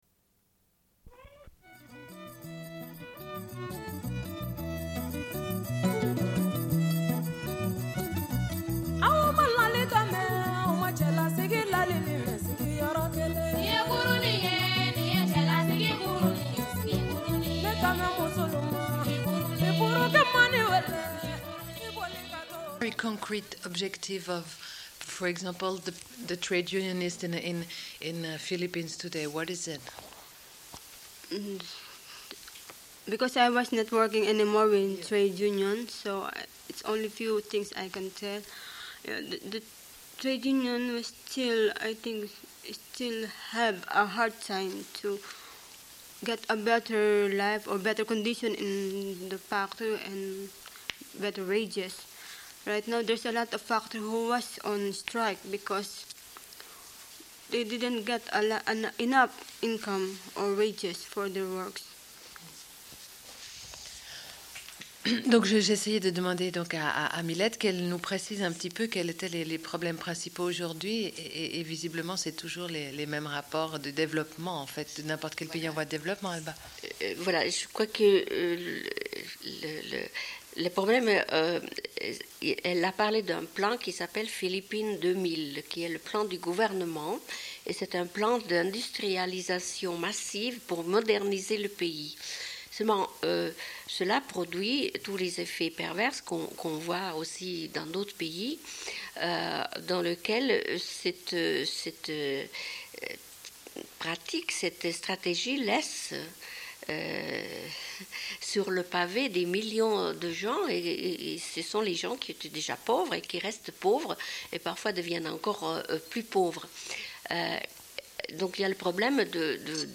Une cassette audio, face A31:35
Début de l'émission manquant.